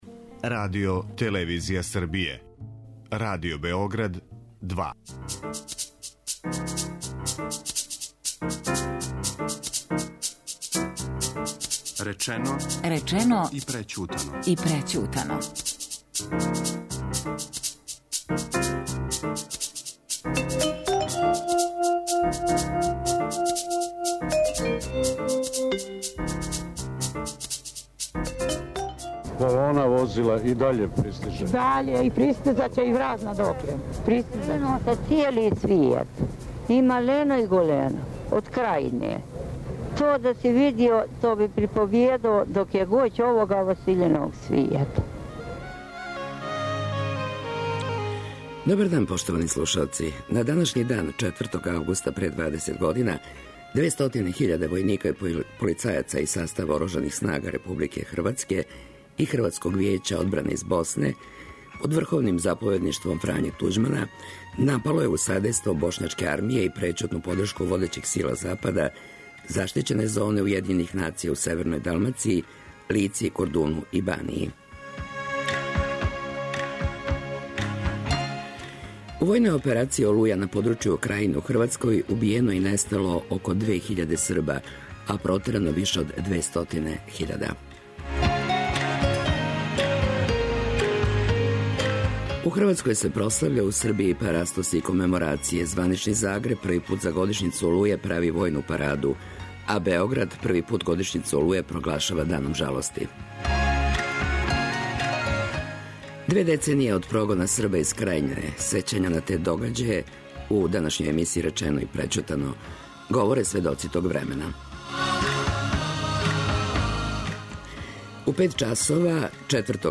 Говоре сведоци тог времена.